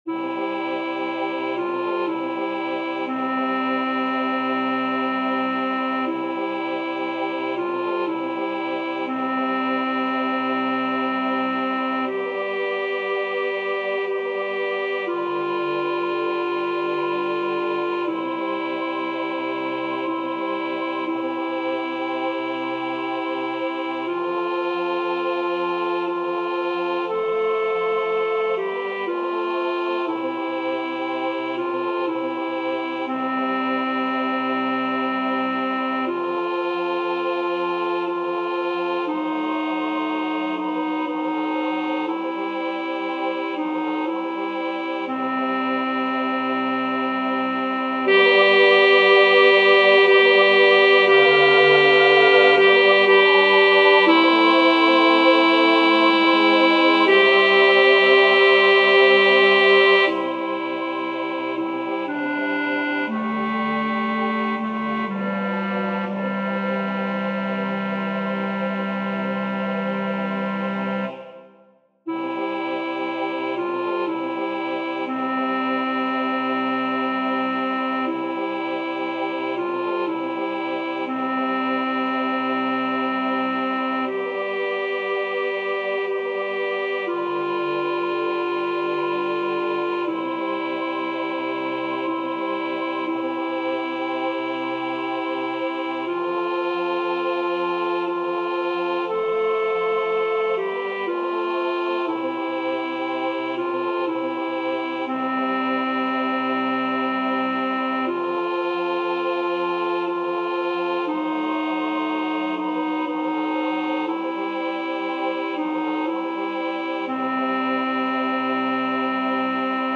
El tempo está marcado como negra=60. Es una canción lenta, en la que hay que estirar al máximo las vocales y respirar de forma muy pausada y tranquila.
Para aprender la melodía os dejo estos MIDIS, con la voz principal destacada por encima del resto.
noche-de-paz-alto.mp3